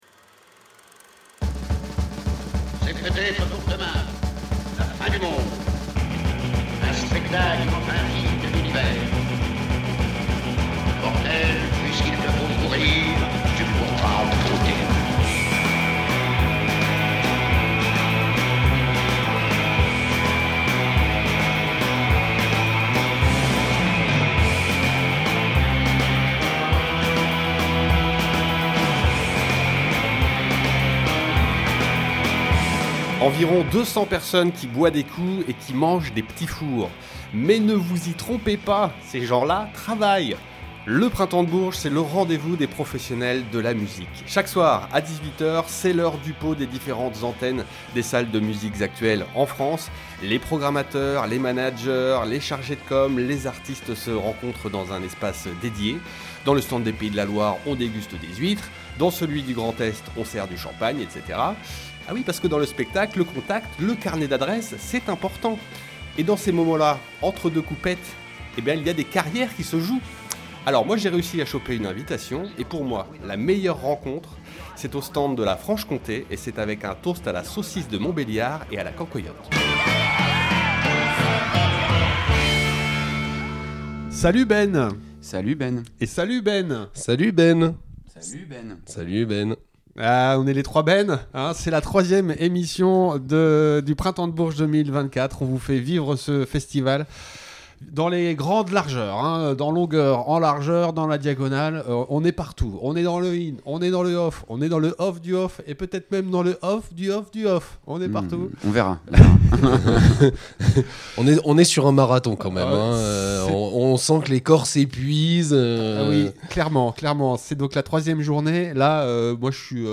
Report au Printemps de Bourges (58:49)